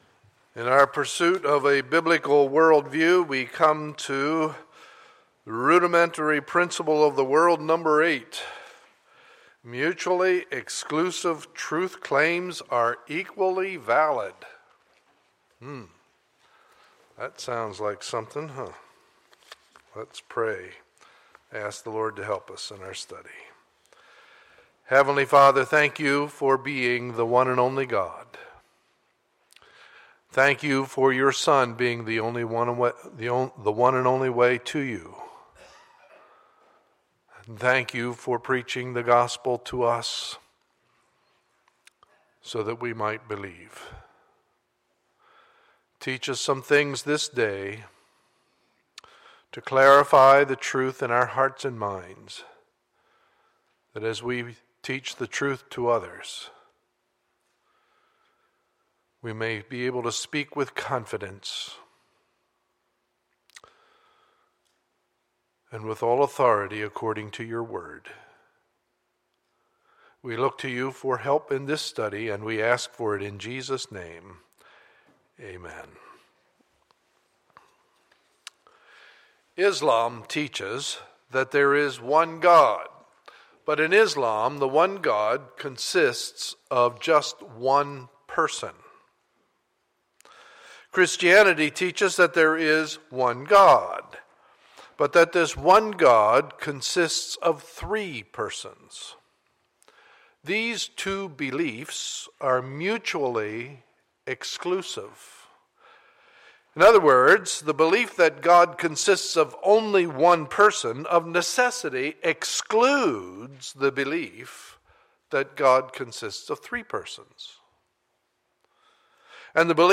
Sunday, May 3, 2014 – Morning Service